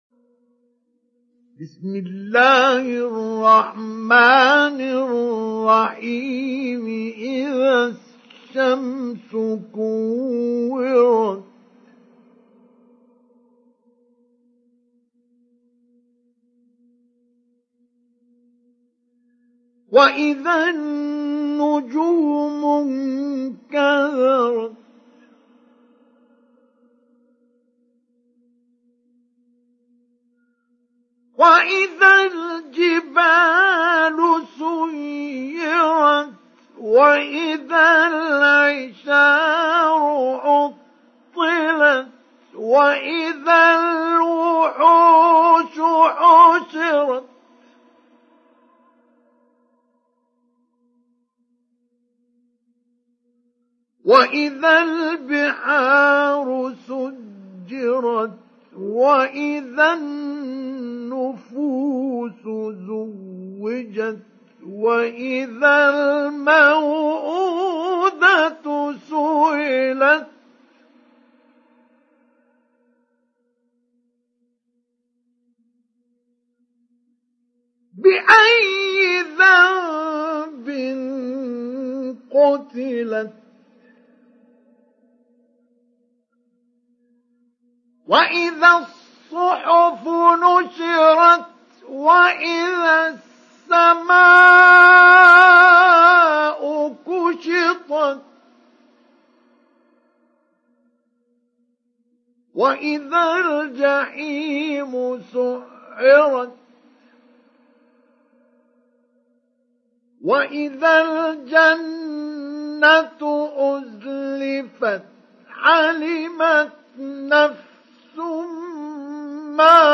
Sourate At Takwir mp3 Télécharger Mustafa Ismail Mujawwad (Riwayat Hafs)
Télécharger Sourate At Takwir Mustafa Ismail Mujawwad